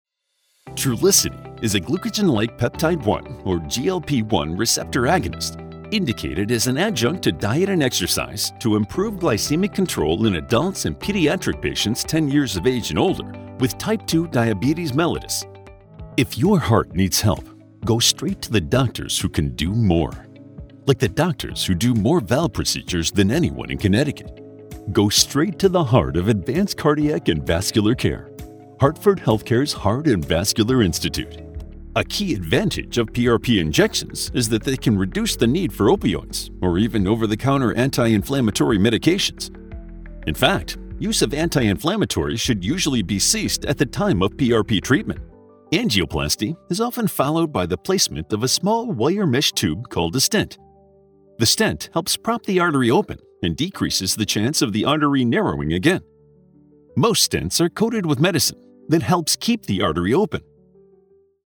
English - USA and Canada
Mid-West, Neutral
I provide the highest broadcast quality voiceover services from my custom-built, personal studio.